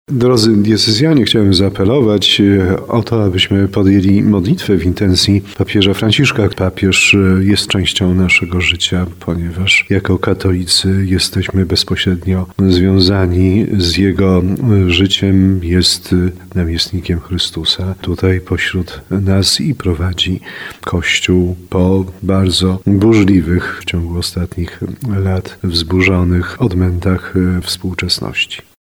O modlitwę w intencji papieża Franciszka apeluje biskup tarnowski Andrzej Jeż. Ojciec Święty choruje na obustronne zapalenie płuc.